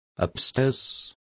Complete with pronunciation of the translation of upstairs.